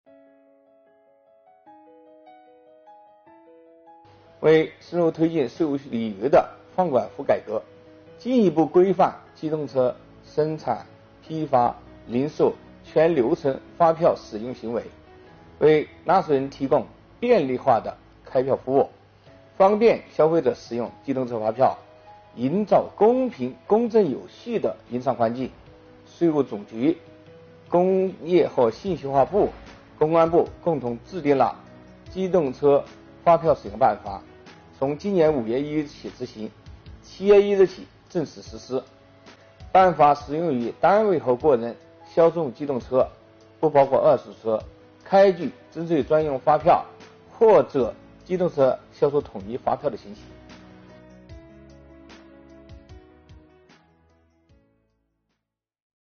近日，由国家税务总局货物和劳务税司副司长张卫担任主讲的最新一期税务讲堂围绕《办法》相关政策规定，进行了详细解读。